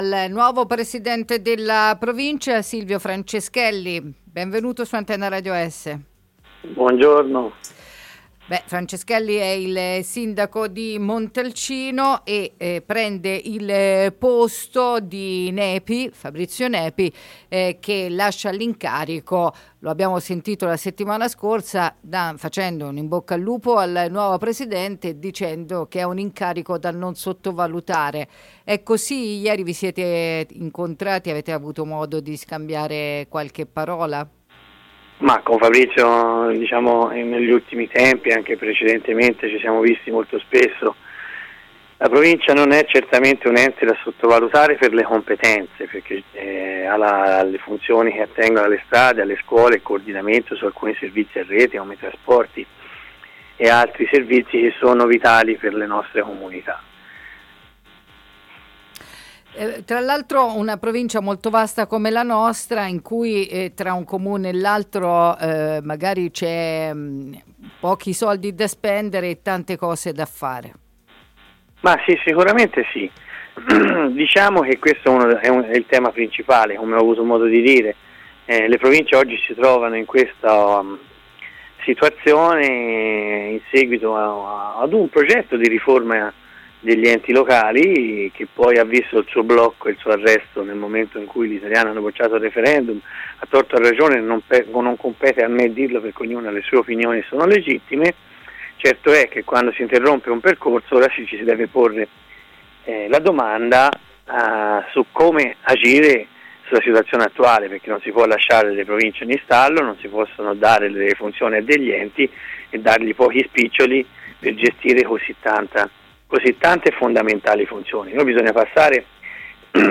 Silvio Franceschelli inizia l’esperienza da Presidente della Provincia chiedendo subito al governo di chiarire il ruolo di questo ente a cui sono affidate competenze fondamentali come strade e scuole ma non i soldi per la gestione dei servizi. “Non si può mandare un fax in cui si chiede quali sono i ponti a rischio ma non si programma anche un intervento nei luoghi a rischio” ha detto ad Antenna Radio Esse nell’intervista dopo la nomina.